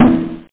sr-16_congaslap.mp3